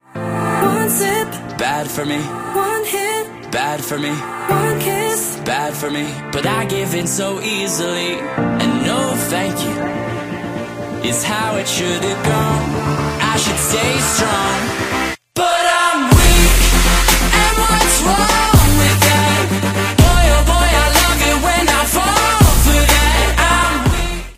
where the pre-chorus and chorus are heard